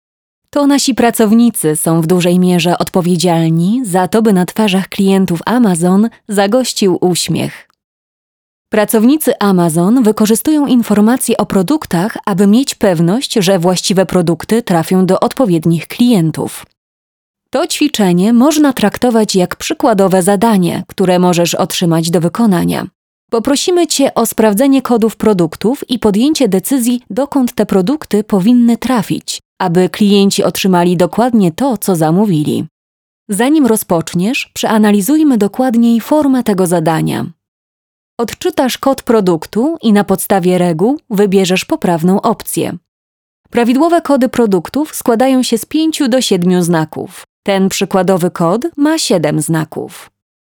Commercial, Natural, Friendly, Warm, Soft
Corporate